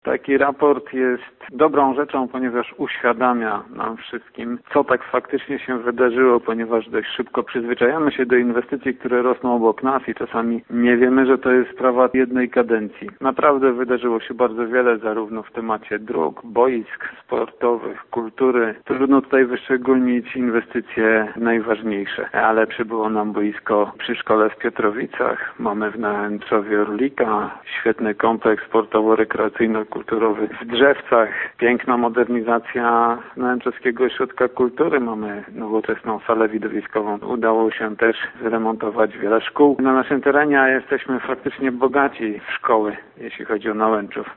„Dotyczy to głównie poprawy jakości dróg, rozbudowy infrastruktury sportowo-rekreacyjnej oraz modernizacji placówek oświatowych i kulturalnych” - mówi burmistrz Nałęczowa: